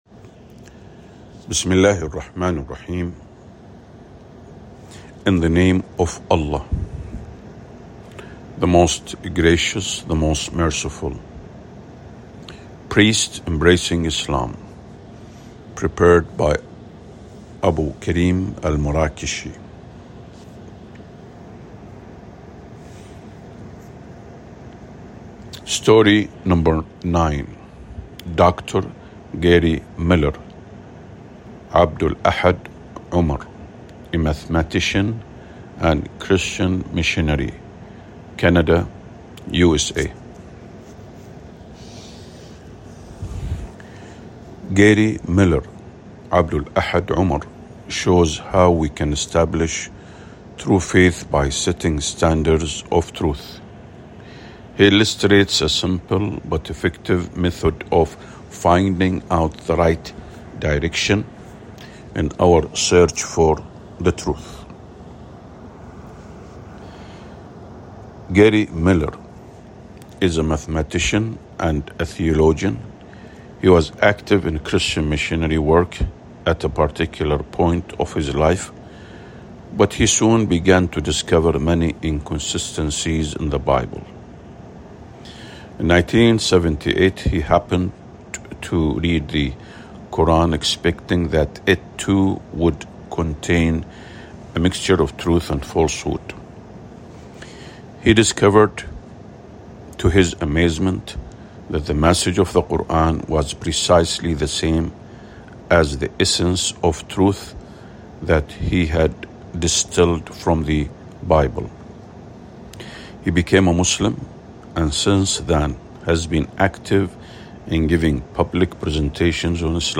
priests-embracing-islam_audiobook_english_9.mp3